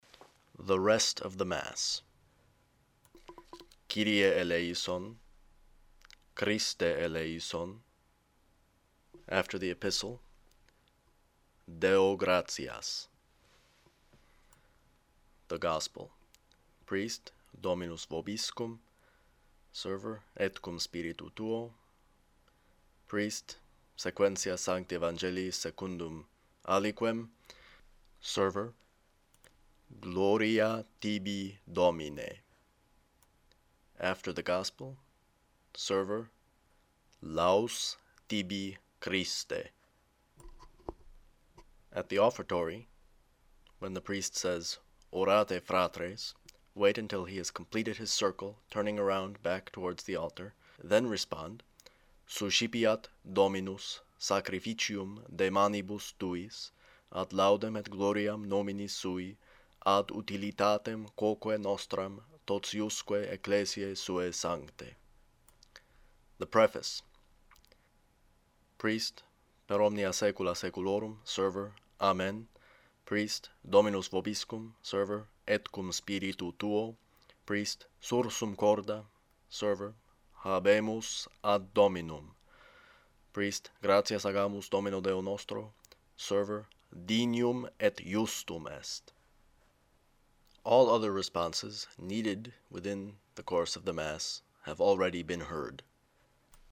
rest_of_the_mass__slow_.mp3